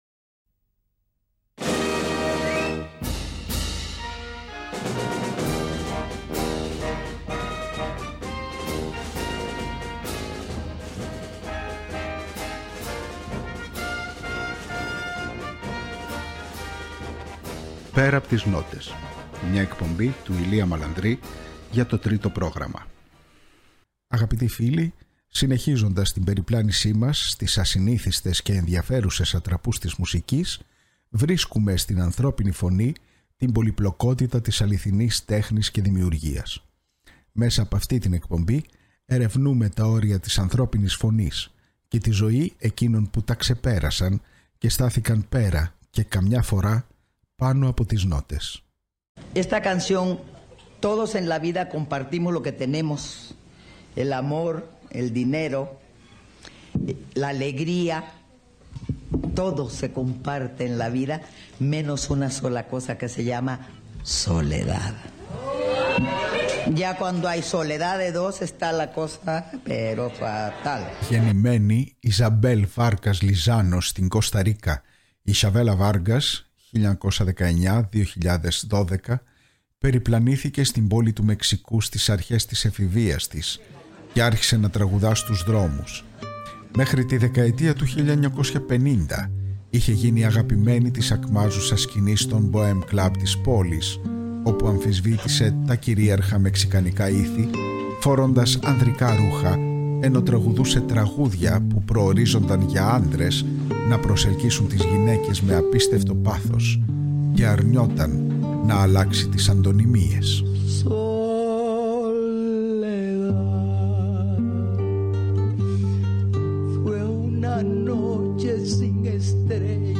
Μέσα από προσωπικές μαρτυρίες, ανέκδοτες ηχογραφήσεις και σημαντικές στάσεις ζωής και καριέρας θα έχουμε την ευκαιρία να ανακαλύψουμε τον καλλιτέχνη «Πέρα από τις νότες» .
Μέσα από σπάνιο ηχητικό αρχείο συνεντεύξεων και άγνωστων ανέκδοτων ηχογραφήσεων ξετυλίγονται τα Πορτραίτα 30 καλλιτεχνών που άφησαν ένα τόσο ηχηρό στίγμα στην τέχνη καταφέρνοντας να γίνουν σημείο αναφοράς και να εγγραφούν στην ιστορική μνήμη, όχι μόνο ως ερμηνευτές αλλά και ως σύμβολα.